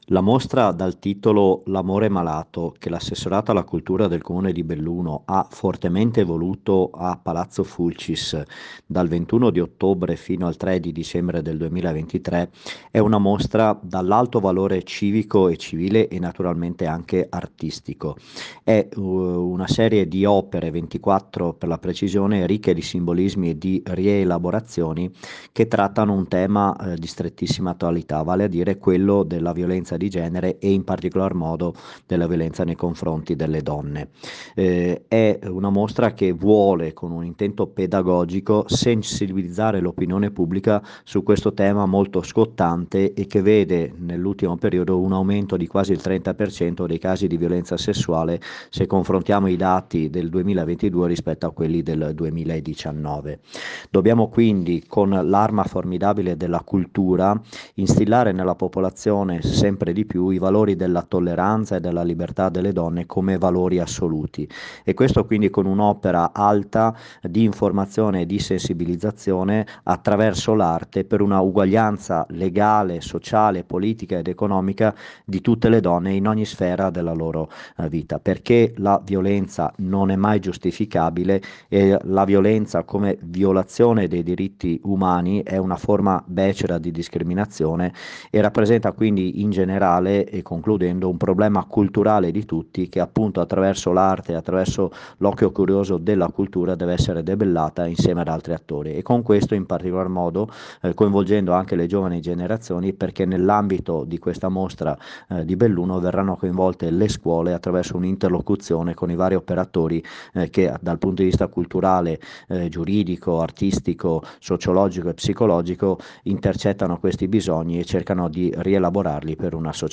RAFFAELE ADDAMIANO, ASSESSORE ALLA CULTURA DEL COMUNE DI BELLUNO